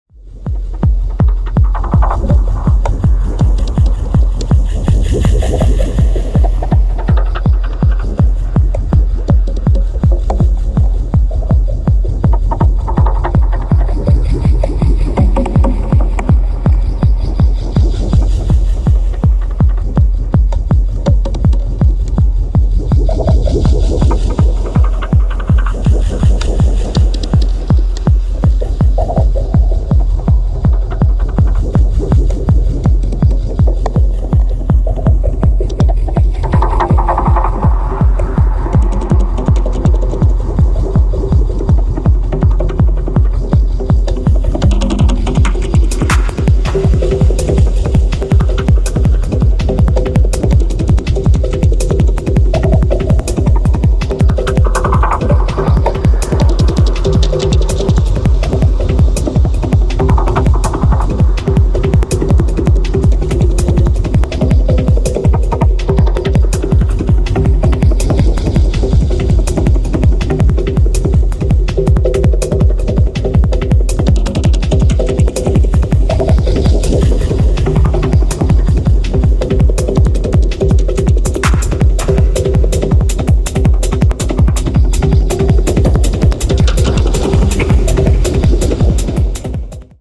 もつれるように転げ回るトライバルなドラムが鮮烈な高速チューン